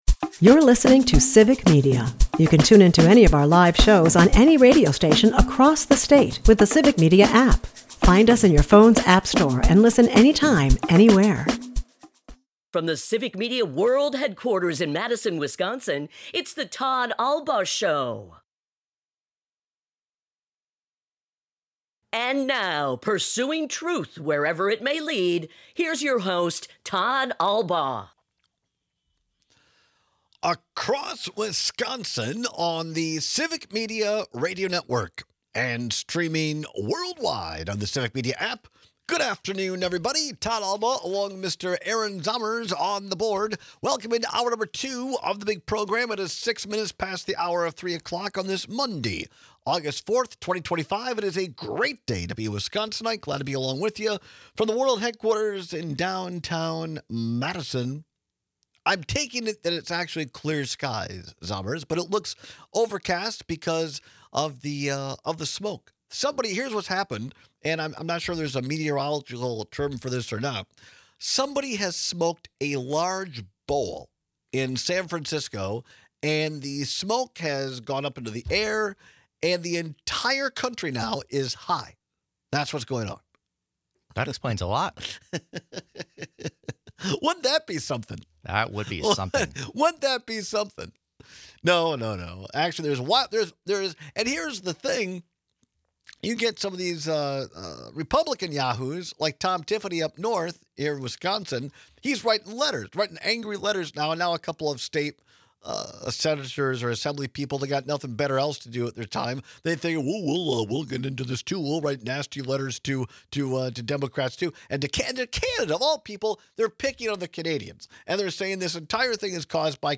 It turns out that this is a complicated and contentious issue, so we take a lot of calls and texts on the topic.